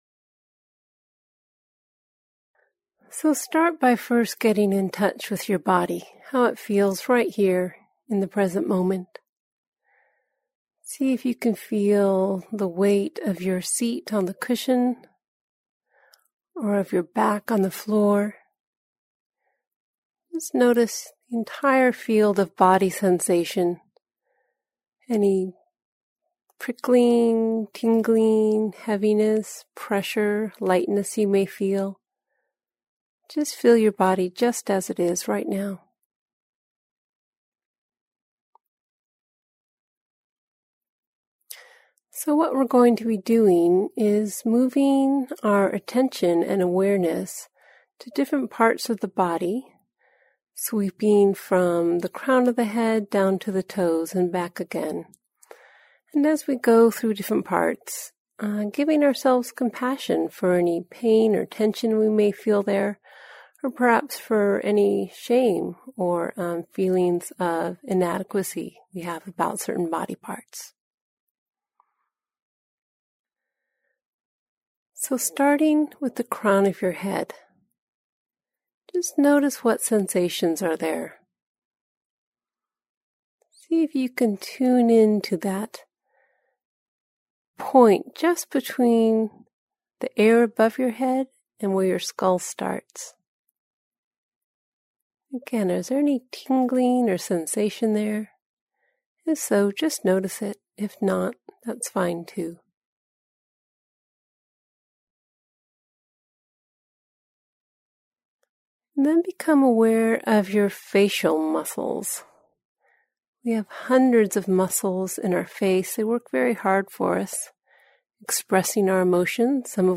Compassionate Body Scan
compassionate-body-scan-april2024.mp3